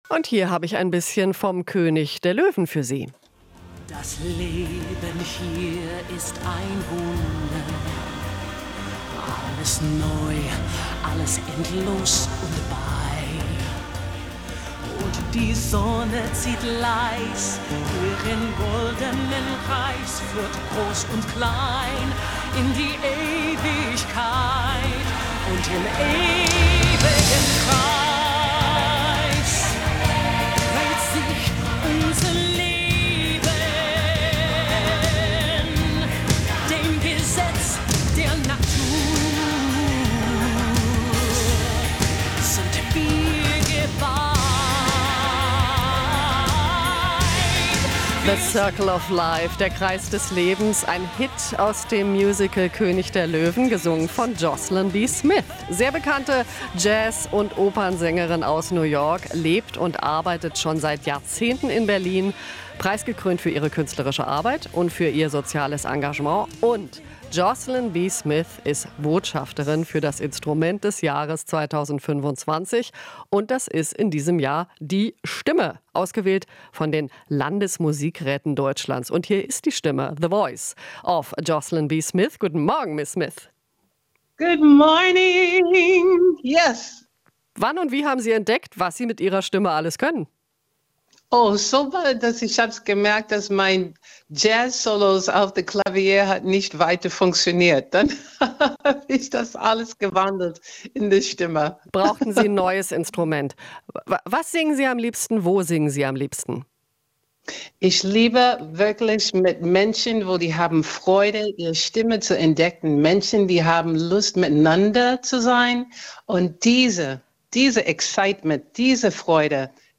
Sängerin Jocelyn B. Smith
Interview - Jocelyn B. Smith: Wenn wir singen, scheint das Licht in uns